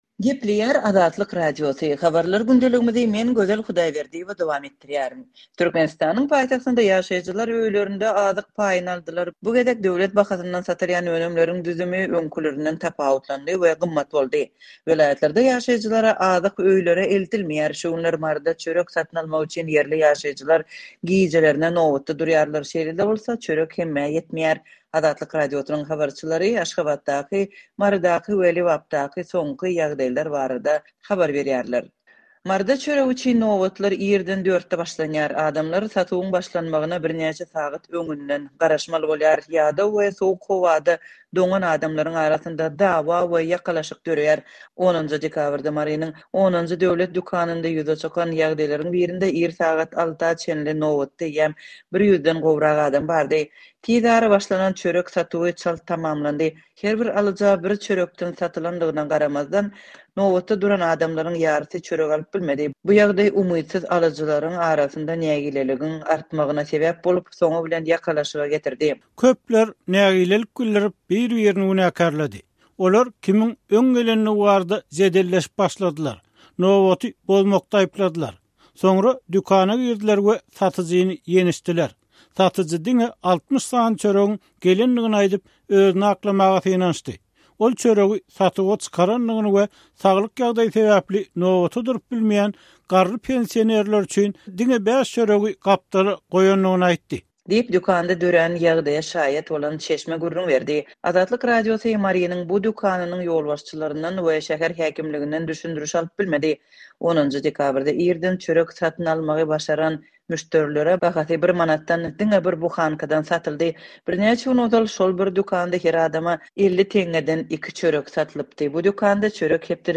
Şu günler Maryda çörek satyn almak üçin ýerli ýaşaýjylar gijelerine nobatda durýarlar, şeýle-de bolsa, çörek hemmä ýetmeýär. Azatlyk Radiosynyň habarçylary Aşgabatdaky, Marydaky we Lebapdaky soňky ýagdaýlar barada habar berdiler.